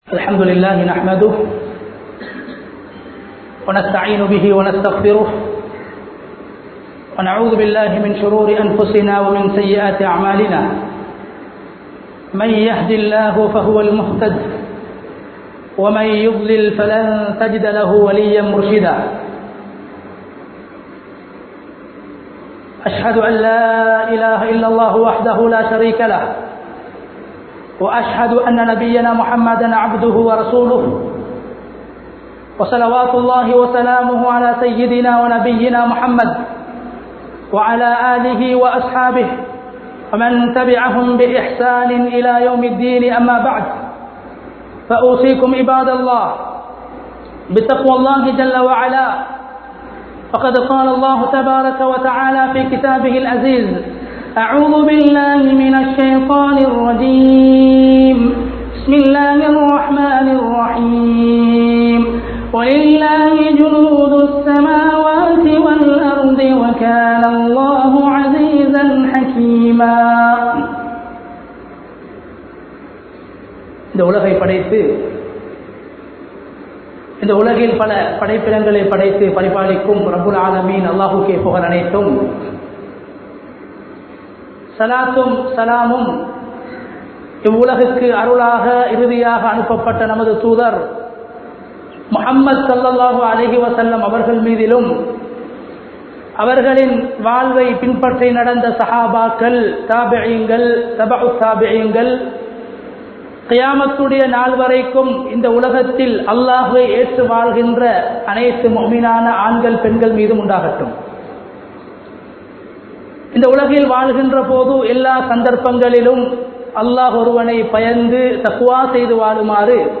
அல்லாஹ்வின் படைகள் (Forces of Allah) | Audio Bayans | All Ceylon Muslim Youth Community | Addalaichenai
Colombo 09, Dematagoda, Mohammediyah Masjidh 2020-06-26 Tamil Download